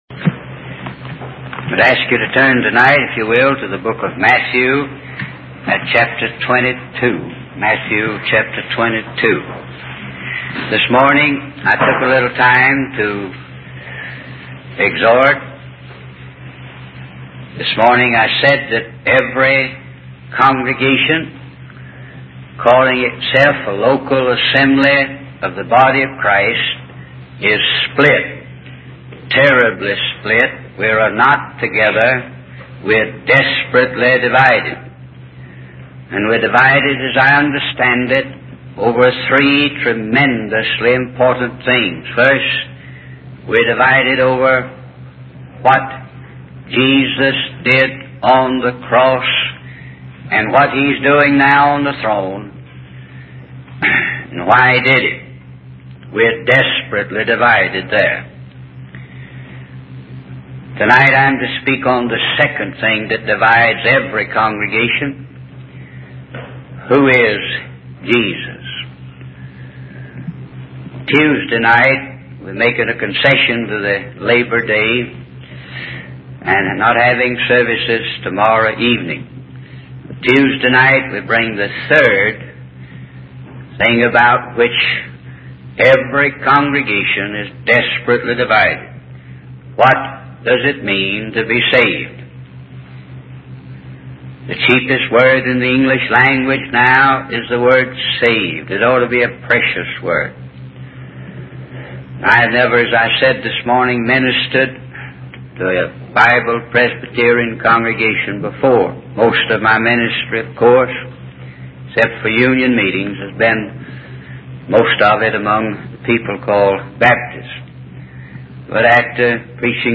In this sermon, the preacher begins by expressing his disinterest in the divisions and disagreements among people. He emphasizes the importance of love and acceptance, regardless of differing views. The preacher then leads the congregation in a prayer, invoking the name of Jesus and asking for mercy.